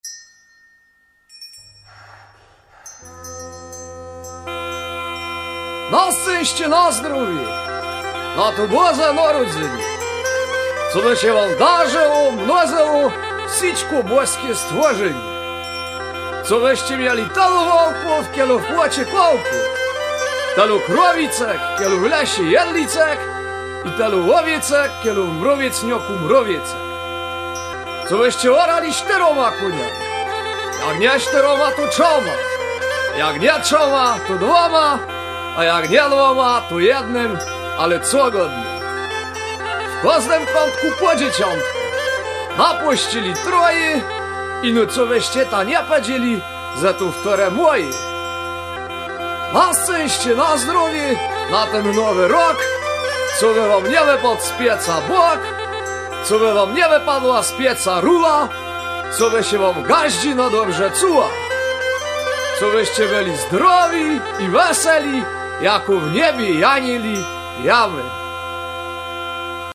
- życzenia świąteczne od kapeli (3,1 Mb)